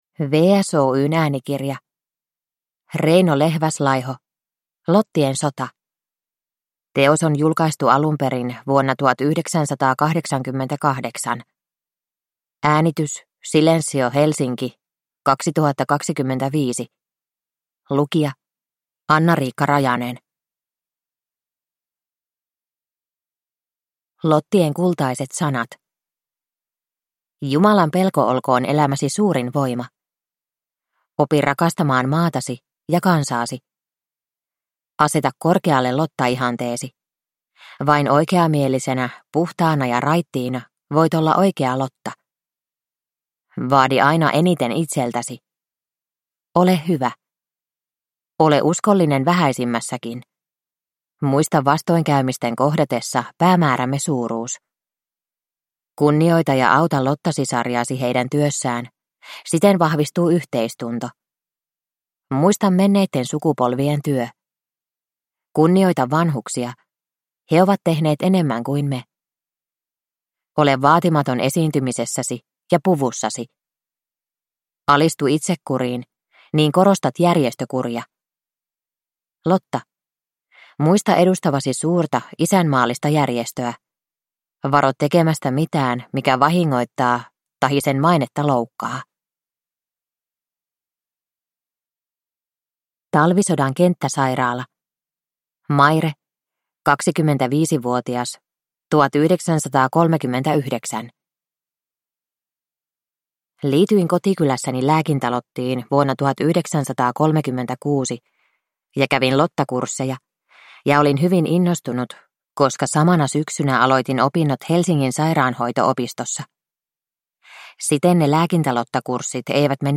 Lottien sota – Ljudbok